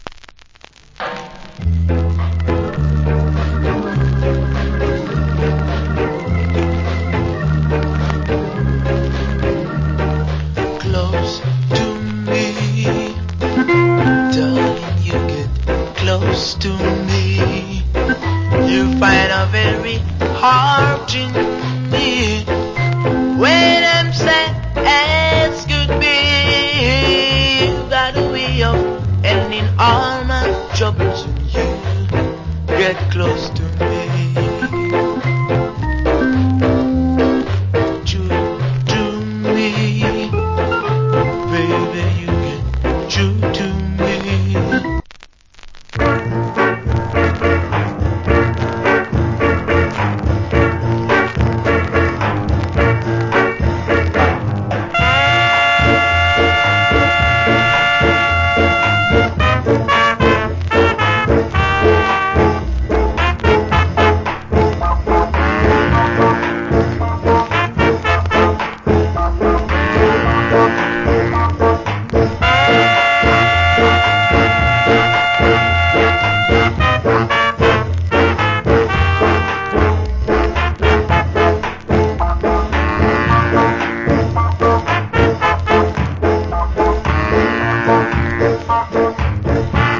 Nice Rock Steady Vocal.